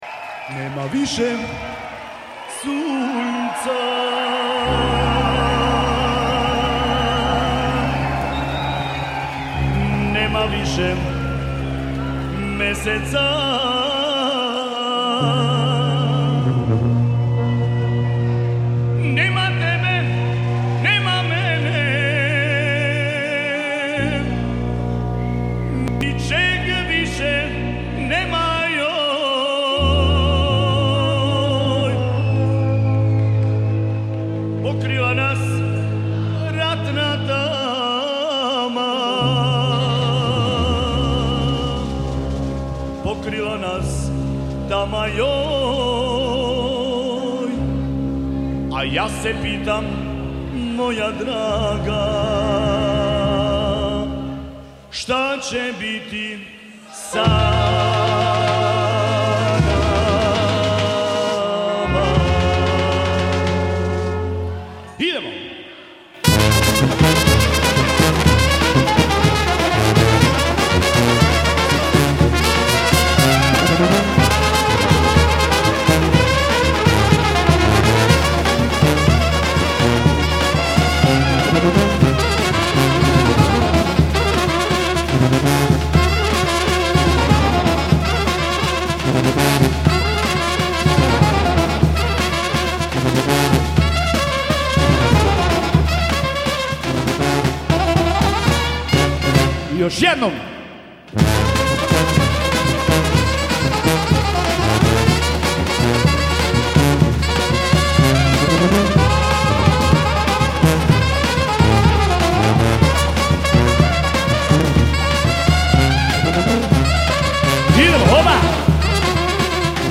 etno glasbe Balkana